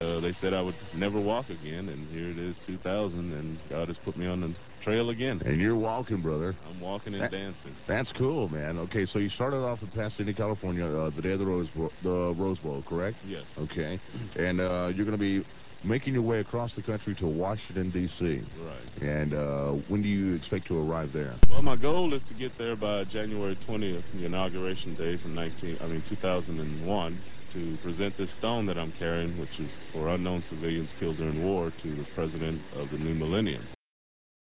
at KYJT radio Yuma, AZ